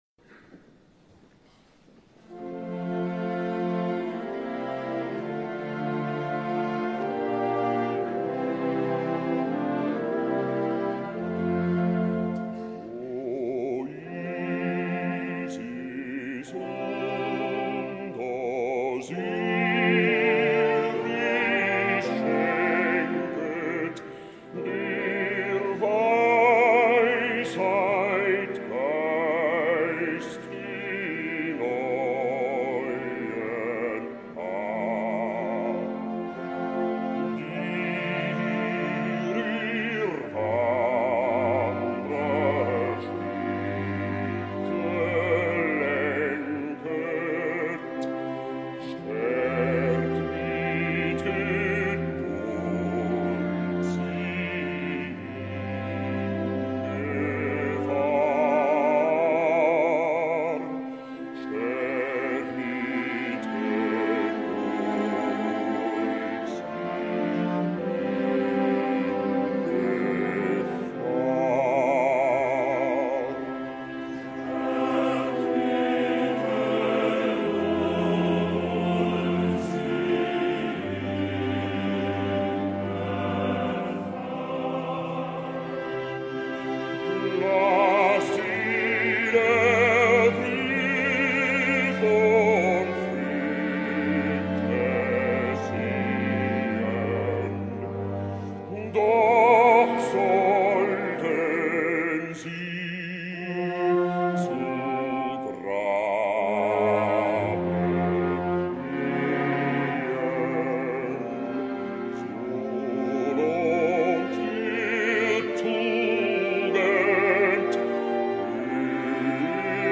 BAJO